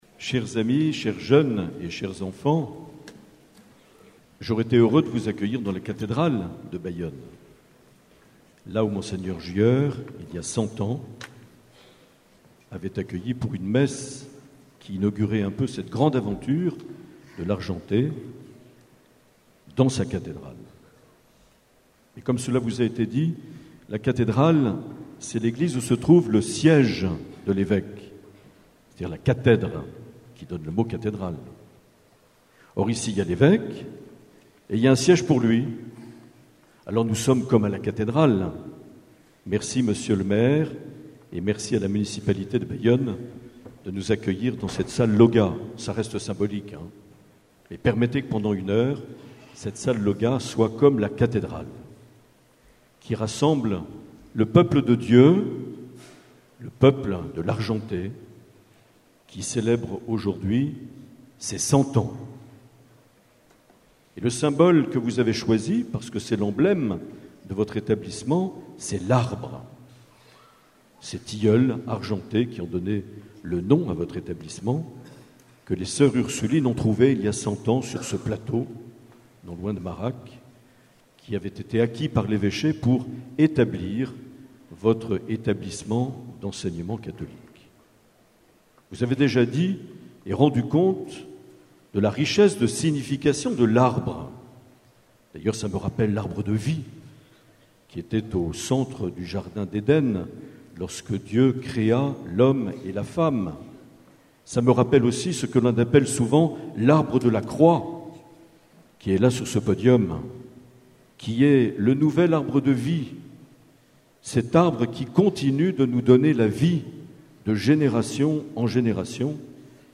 27 janvier 2020 - Centenaire de Largenté à Bayonne - Salle Lauga